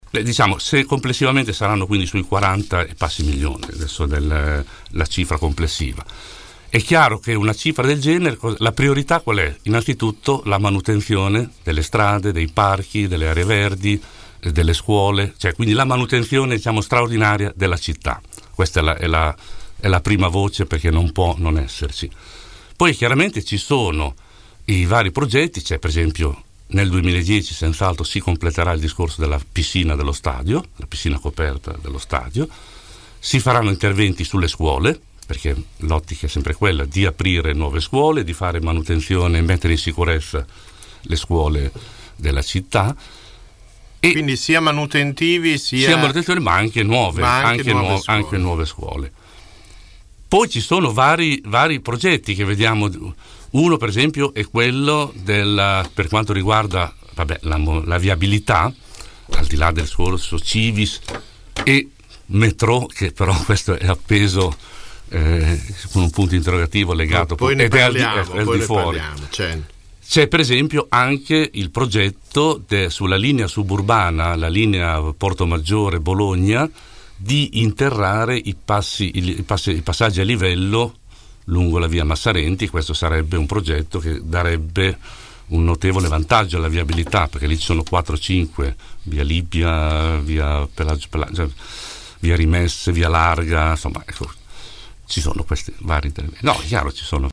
L’assessore al bilancio ai nostri microfoni parla di tagli, tasse e investimenti.